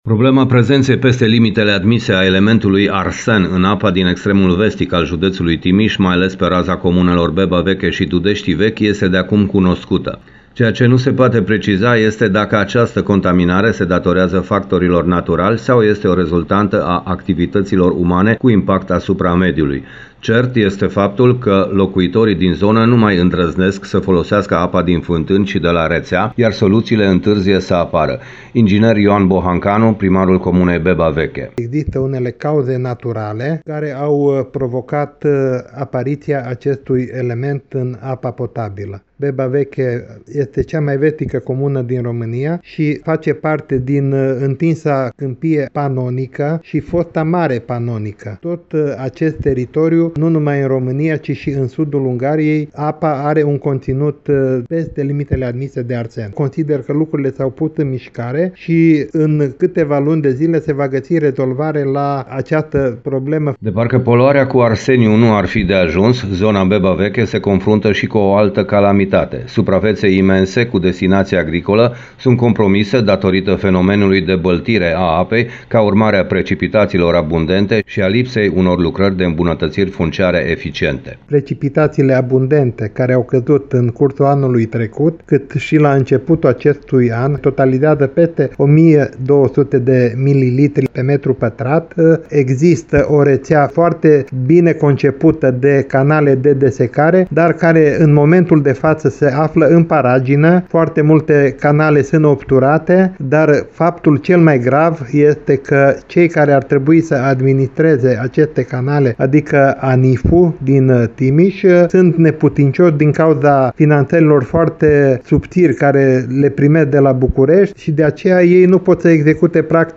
Aceasta este tema ediţiei de astăzi, 5 martie 2015, a emisiunii „Vestul Zilei”, difuzată imediat după ştirile orei 18.00, avându-l invitat pe dl Ioan Bohâncanu, primarul localităţii Beba Veche din judeţul Timiş.